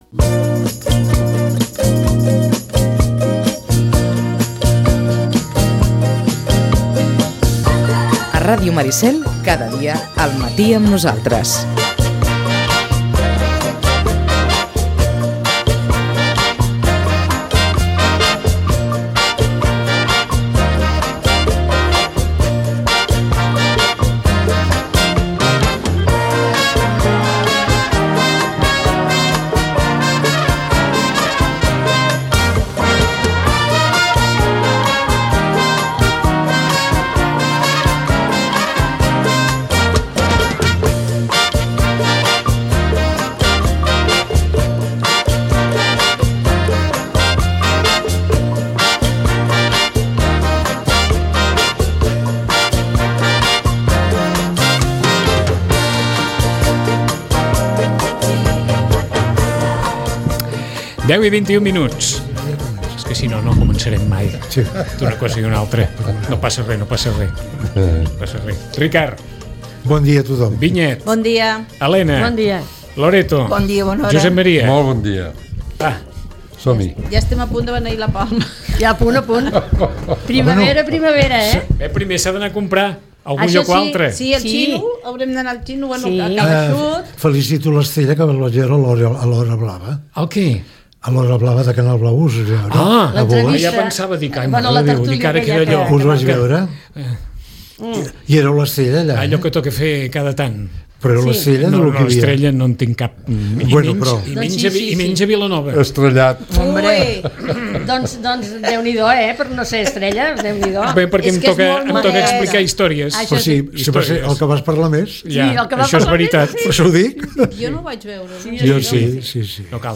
Tertúlia